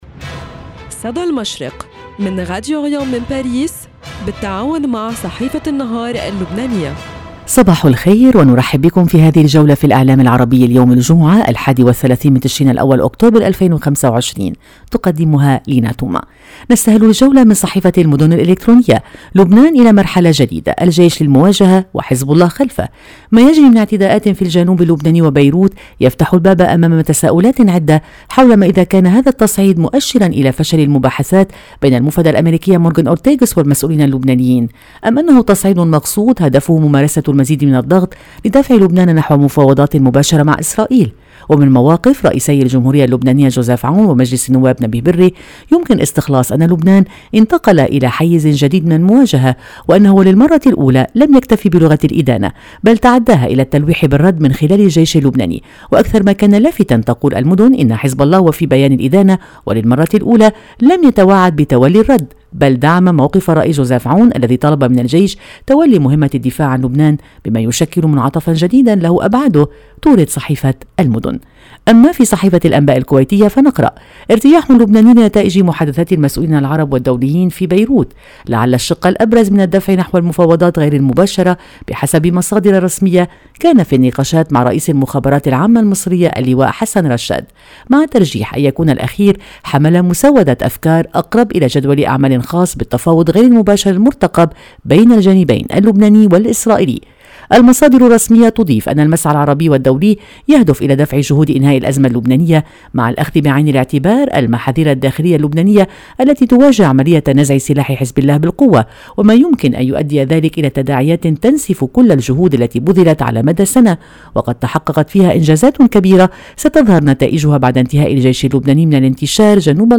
صدى المشرق – نافذتكم اليومية على إعلام الشرق، كل صباح في تعاون بين راديو أوريان إذاعة الشرق من باريس مع جريدة النهار اللبنانية، نستعرض فيها أبرز ما جاء في صحف ومواقع الشرق الأوسط والخليج العربي من تحليلات مواقف وأخبار،  لنرصد لكم نبض المنطقة ونحلل المشهد الإعلامي اليومي.